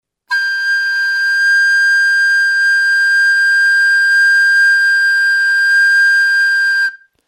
A nota Sol´ aguda.
nota sol agudo